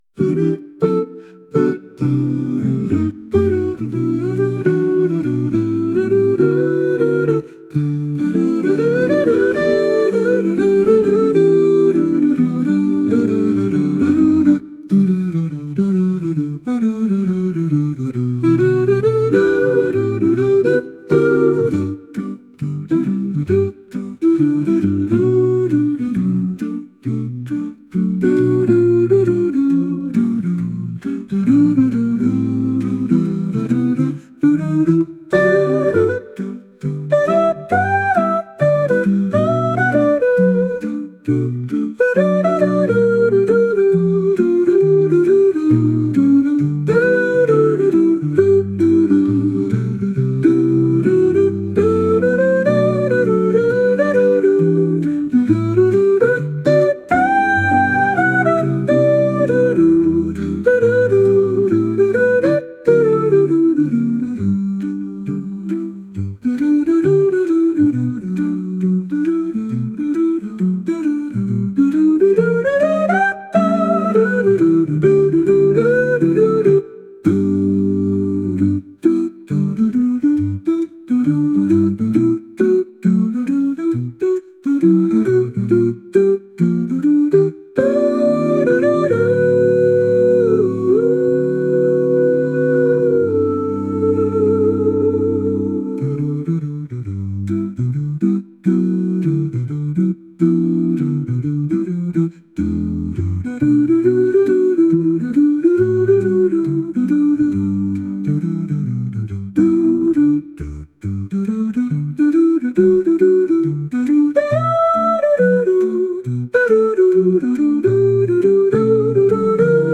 アカペラを含んだボサノバ曲です。